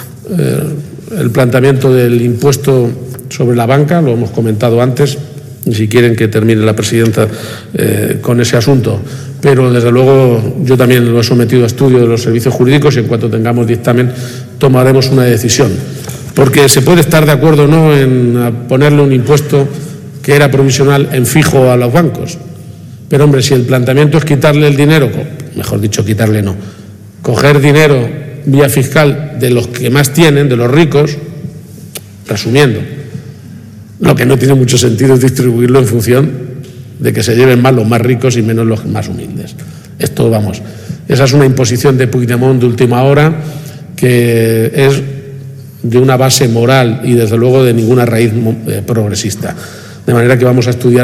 Presidente Lunes, 10 Marzo 2025 - 1:15pm El jefe del Ejecutivo regional ha declarado hoy que los servicios jurídicos de la Junta estudian cómo actuar ante la decisión estatal del "impuesto a la banca". garciapage_impuesto_a_la_banca.mp3 Descargar: Descargar